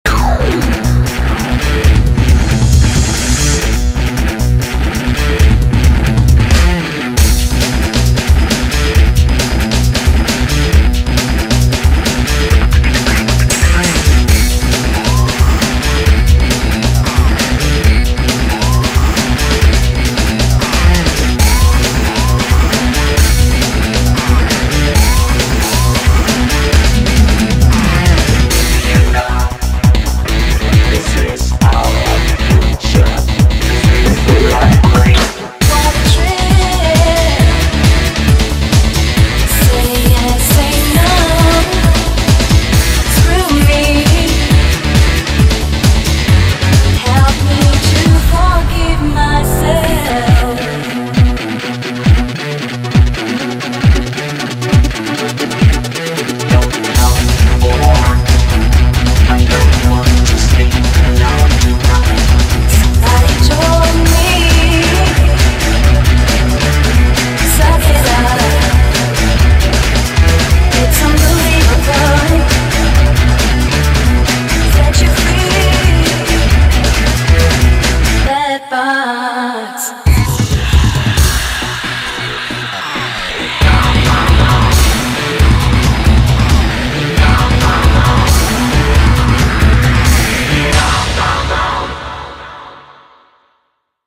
BPM135
Audio QualityPerfect (High Quality)
Less loud and thrash-y, and more soothingly techno.